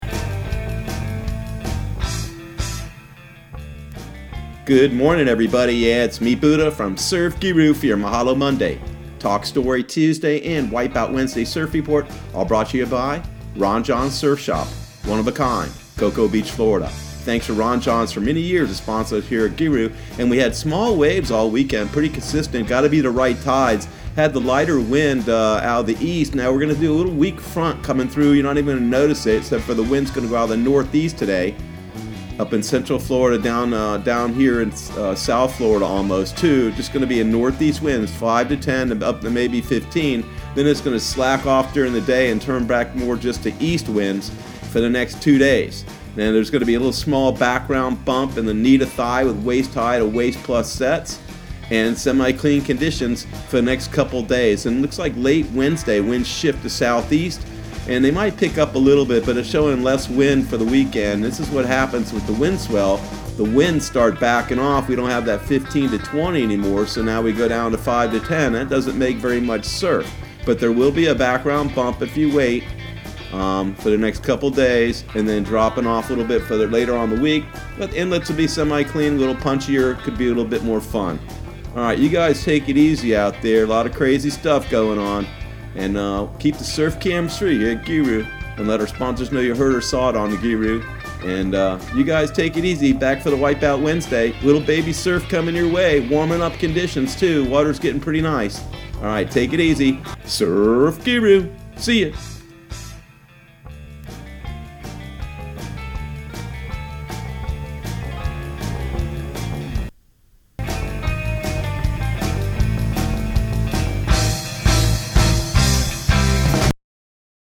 Surf Guru Surf Report and Forecast 03/16/2020 Audio surf report and surf forecast on March 16 for Central Florida and the Southeast.